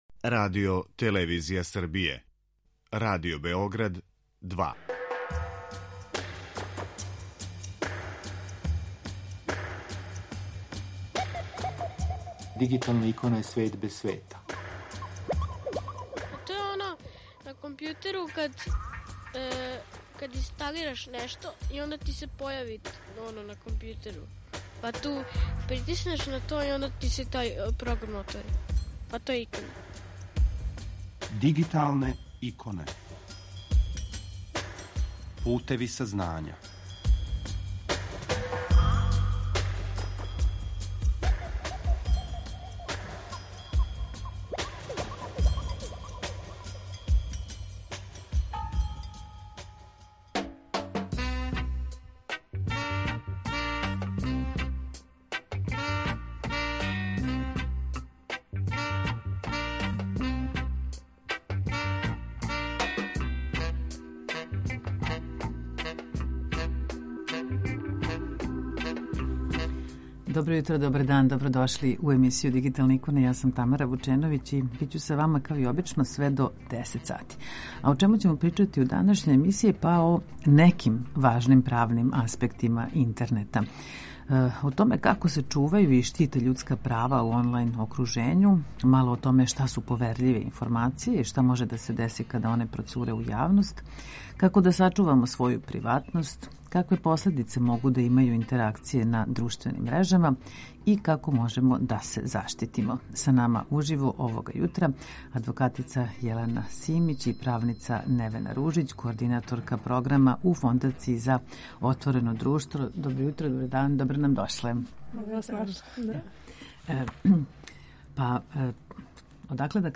Kако се чувају и штите људска права у онлајн окружењу, шта су поверљиве информације и шта може да се деси када оне процуре у јавност, како да сачувамо своју приватност, какве последице могу да имају интеракције на друштвеним мрежама и како можемо да се заштитимо? Са нама уживо